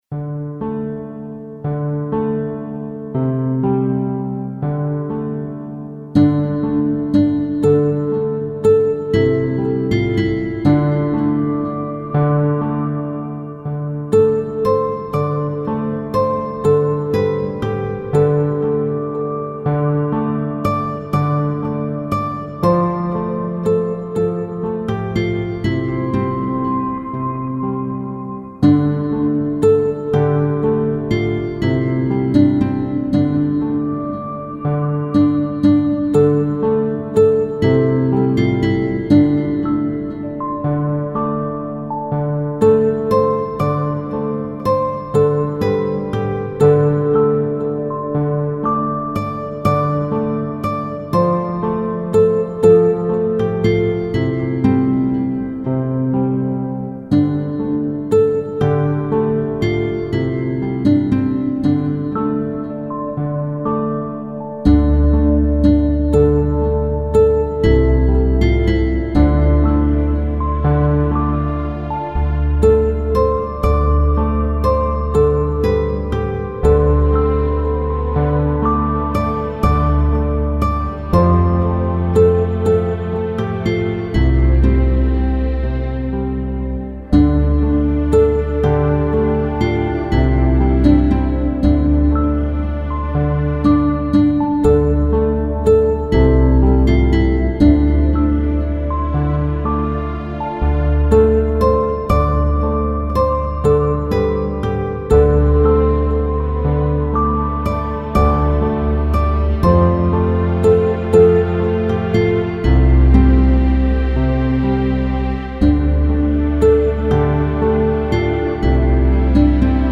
traditional English ballad
Traditional, England (UK)
free karaoke
instrumental, 3:08 – 3/4